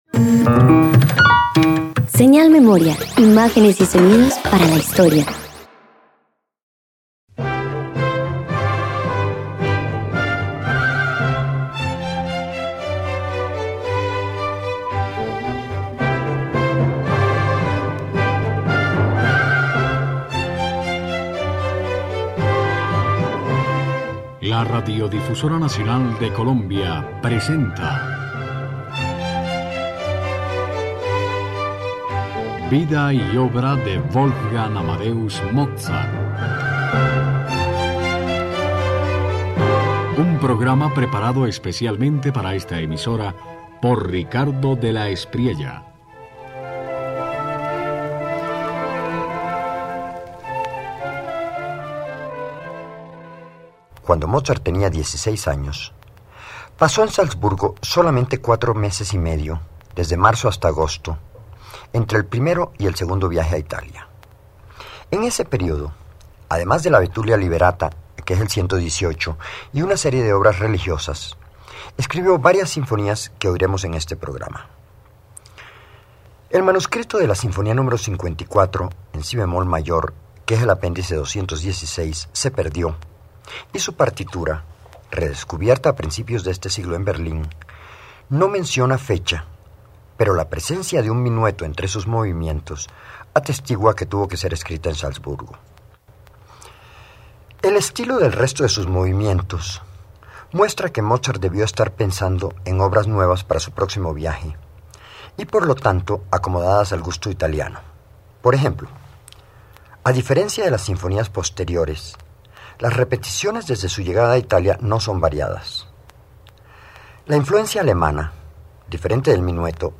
El primer Mozart sinfónico aprende a variar sin desbordar: desarrollos breves, vientos activos, pero medidos, violas aún gemelas. En la n.º 42, la imitación despierta y en la n.º 12, el ritmo ternario se vuelve elegancia en movimiento.